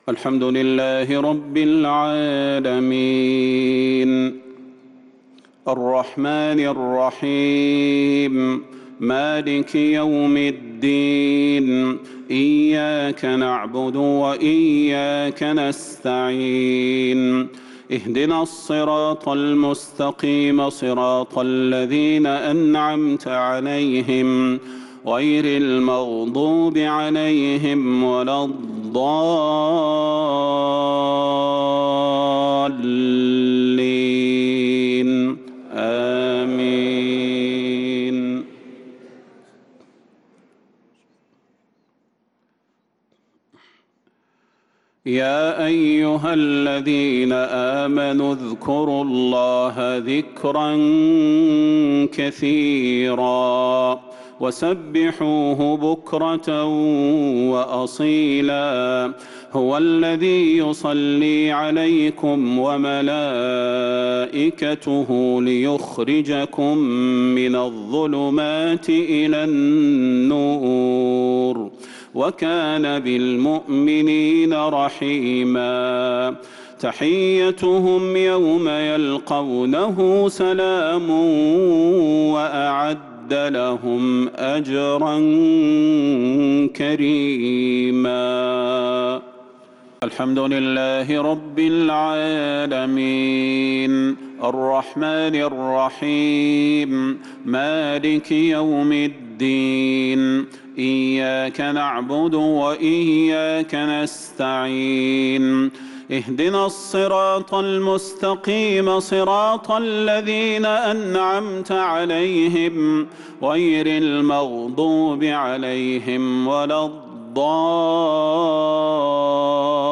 صلاة الجمعة 8-8-1446هـ من سورة الأحزاب 41-48 | Jumaah prayer from Surat al-Ahzab 7-8-2025 > 1446 🕌 > الفروض - تلاوات الحرمين